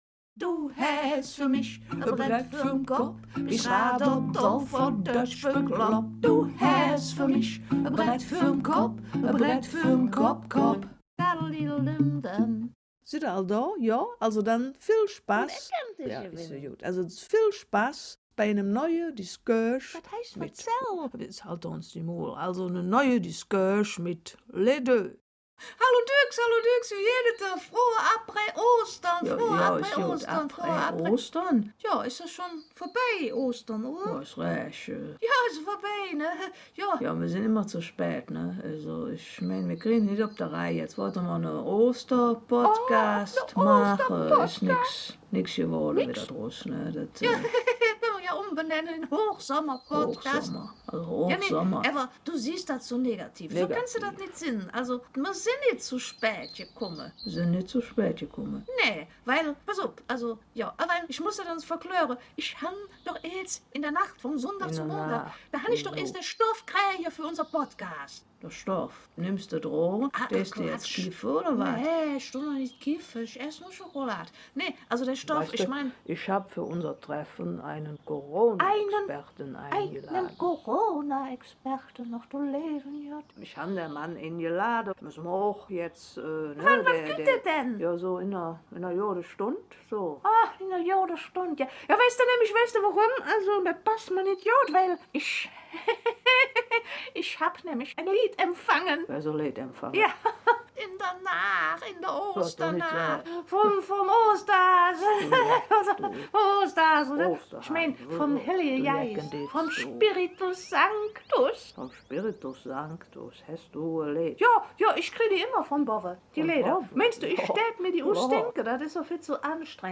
Rheinischer Podcast
Volksweise